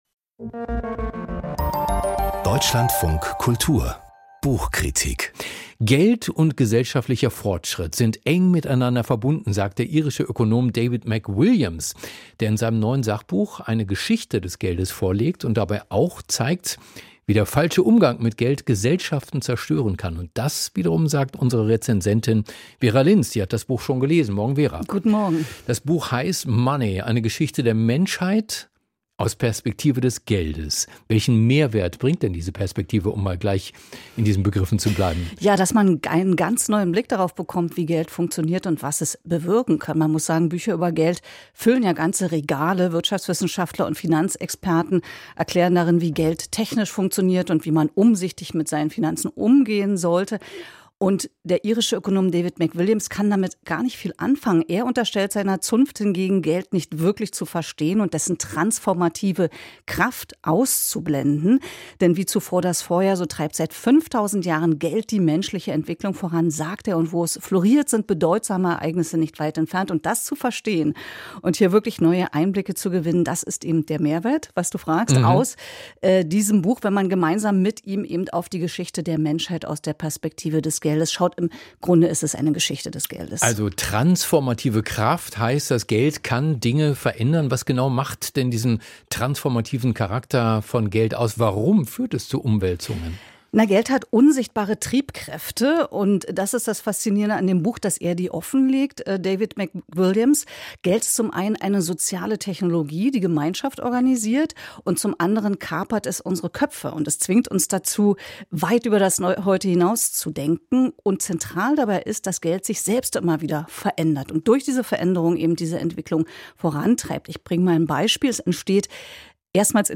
Buchkritik: "Money" von David McWilliams